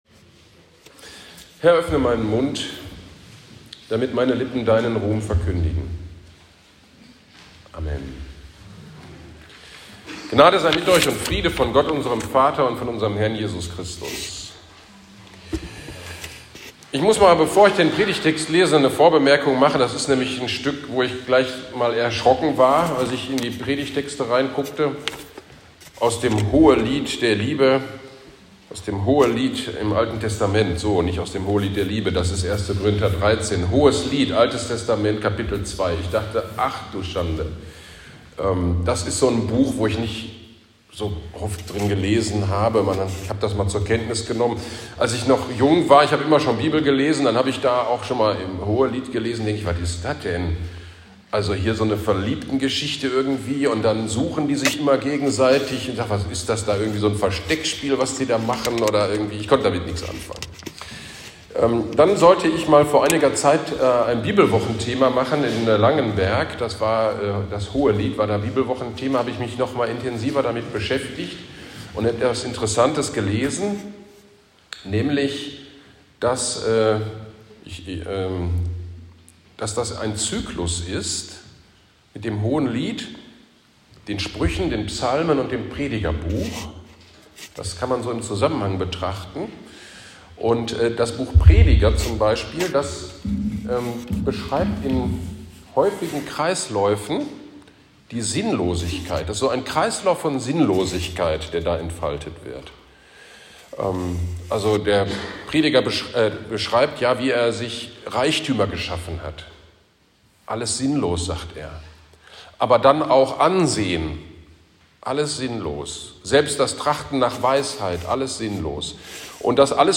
GD am 2. Advent 2022 Predigt zu Hohelied 2,8-14 - Kirchgemeinde Pölzig